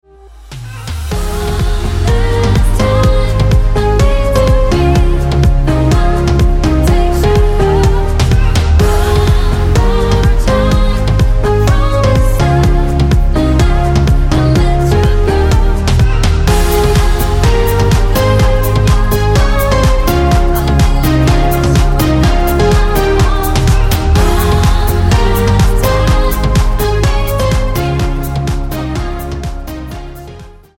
Tonart:Ab mit Chor
Die besten Playbacks Instrumentals und Karaoke Versionen .